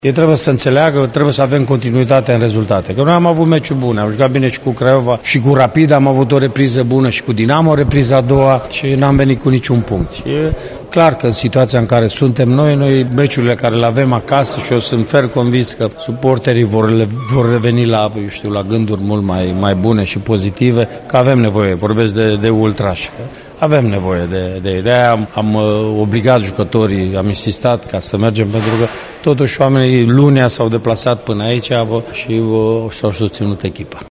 După meciul de pe Cluj Arena, Mircea Rednic și-a atenționat elevii că trebuie să aibă continuitate în rezultate: